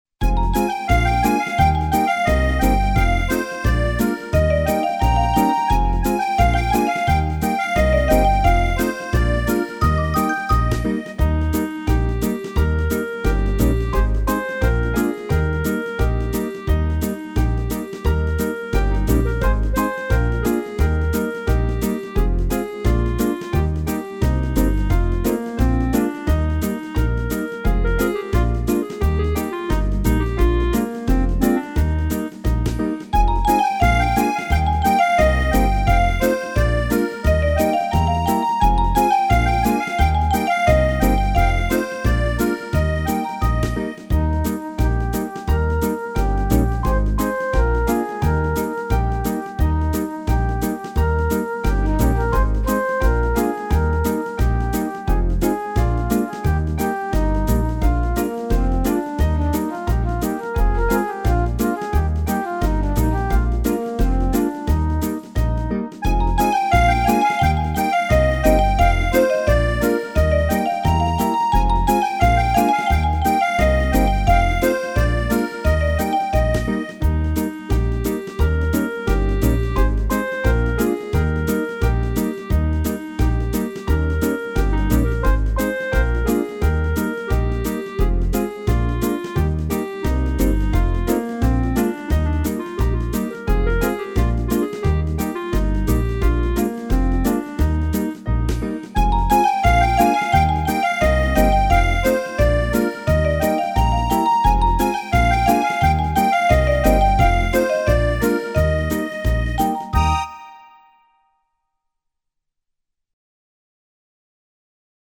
Слушать или скачать минус
Песни и музыка для танцев